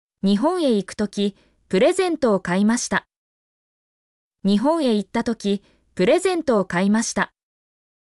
例文：
mp3-output-ttsfreedotcom-45_eby6KtS4.mp3